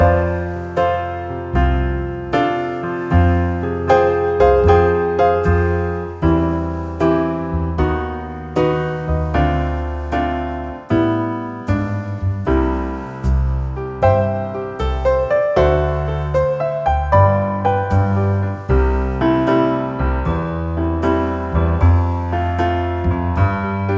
No Drum Kit Duets 3:30 Buy £1.50